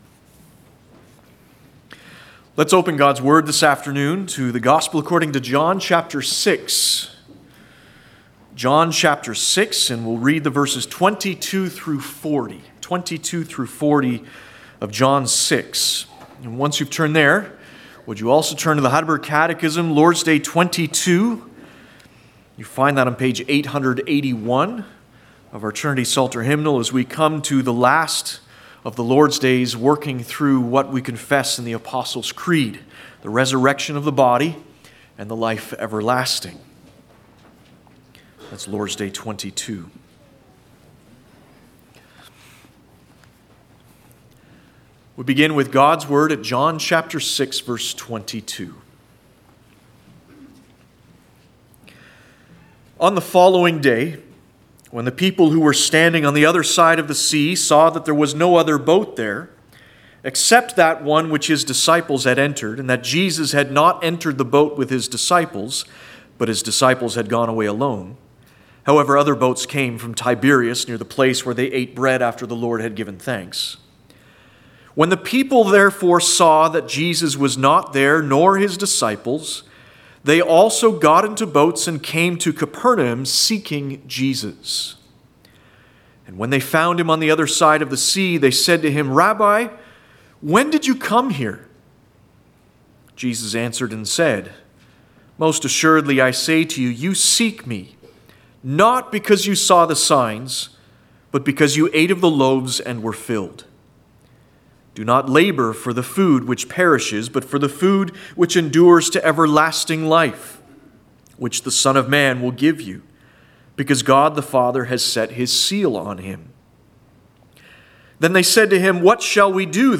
Passage: John 6:22-40 Service Type: Sunday Afternoon « Redemption and Costly Sacrifice Like Job